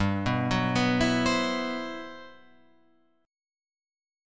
GM13 Chord